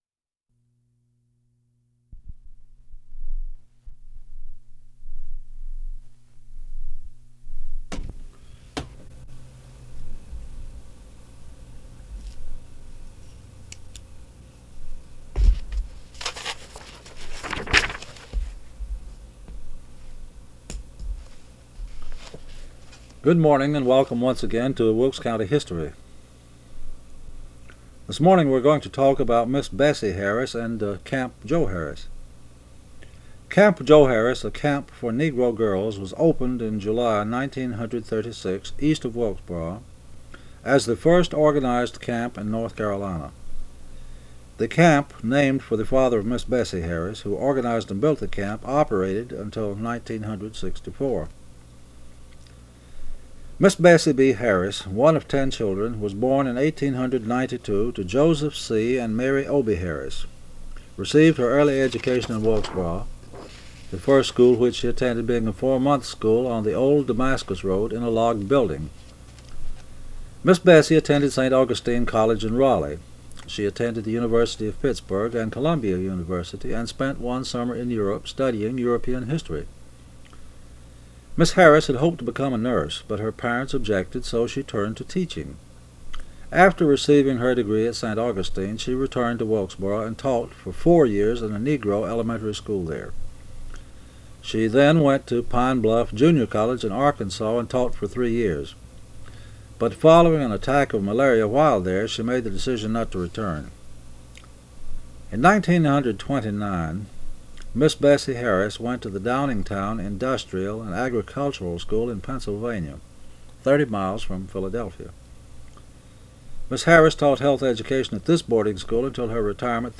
Reel to Reel Tape
Oral history--North Carolina.
Oral histories (literary genre)